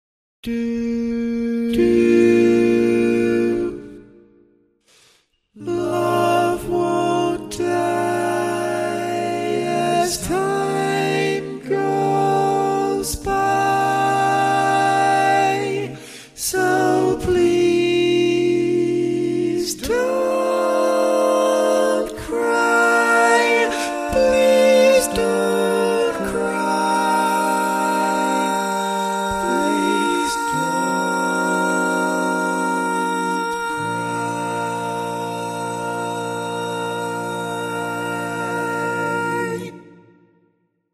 Key written in: B♭ Major
Type: Barbershop
Tenor melody.